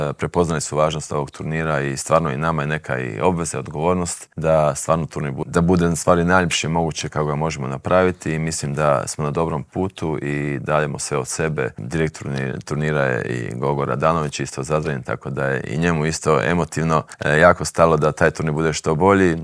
U dvorani Krešimira Ćosića na Višnjiku očekuje nas ekipno prvenstvo Europe, a u Intervjuu tjedna Media servisa ugostili smo predsjednika Hrvatskog stolnoteniskog saveza Zorana Primorca